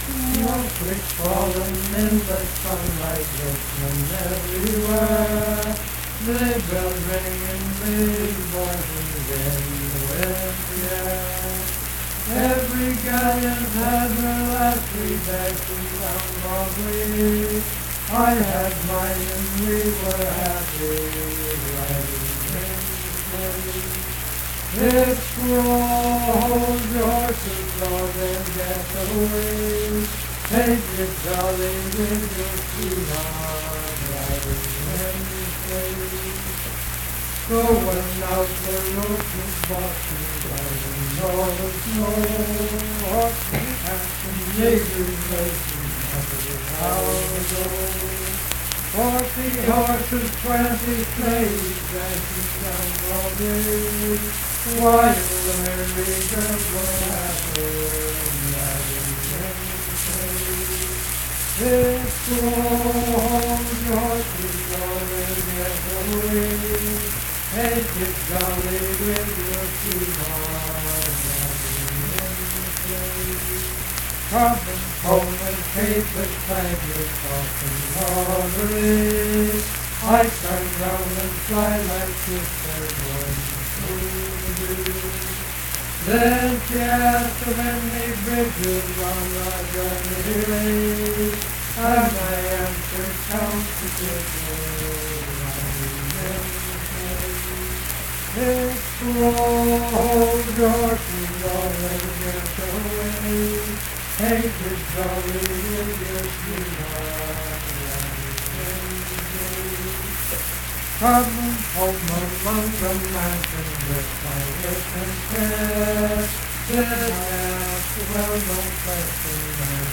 Unaccompanied vocal music
Voice (sung)
Richwood (W. Va.), Nicholas County (W. Va.)